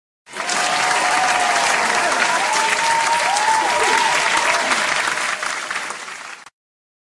Ap Aplauso Sound Button - Free Download & Play